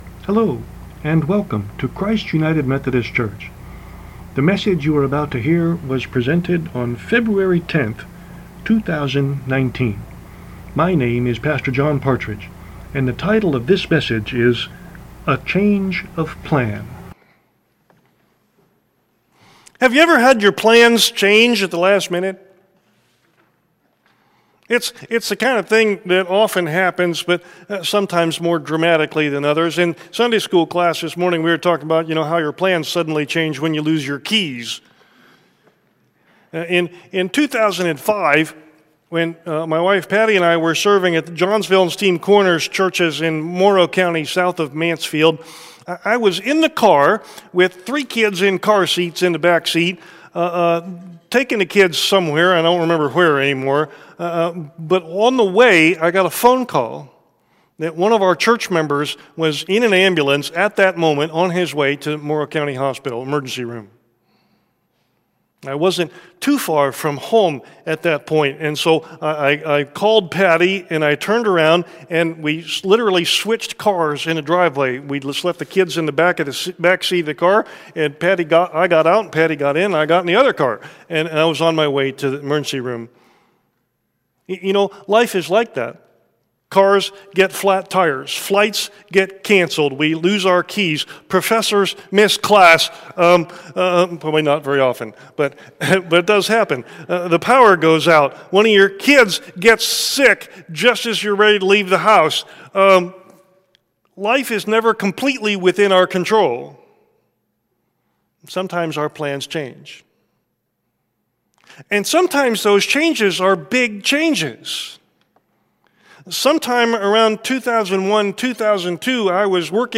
Cassaday-Turkle-Christian Funeral and Cremation Service
Reflections for A Celebration of Memories